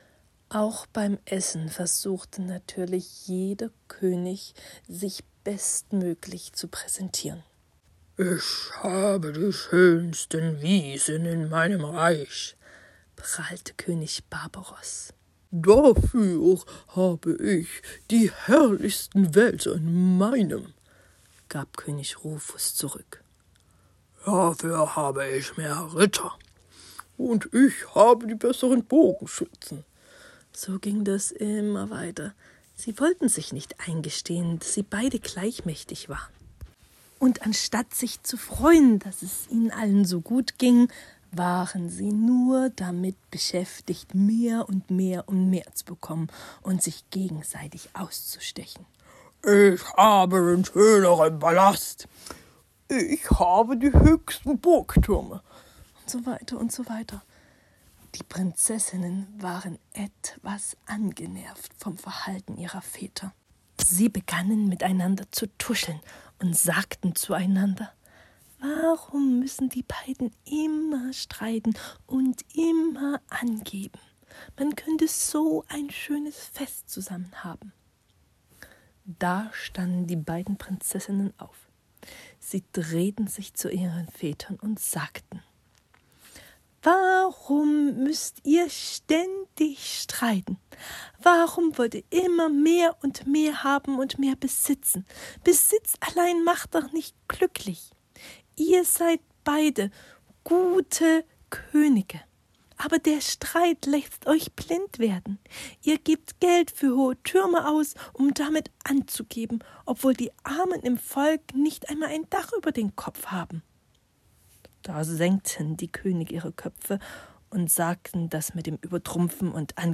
Hörbuchgeschichten